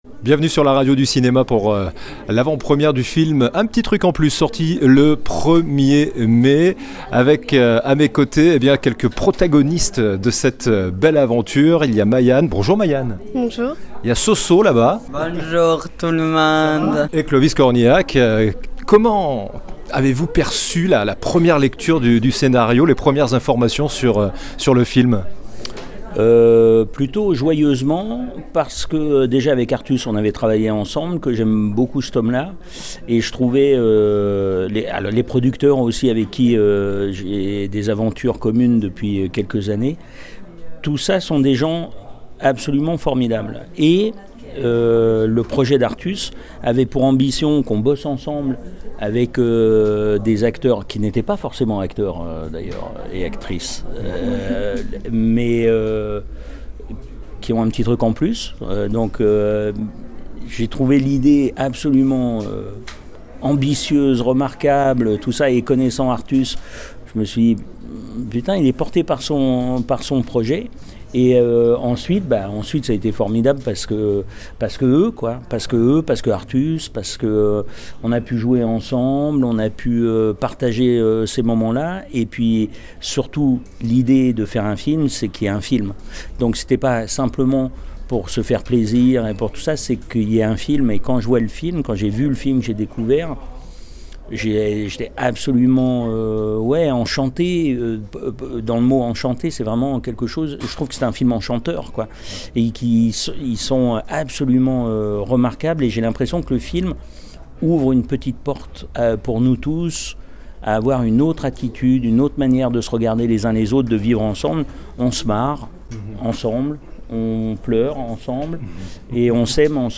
“Un P'tit Truc en Plus”, une comédie ambitieuse. Interview de Clovis Cornillac pour la radio du cinema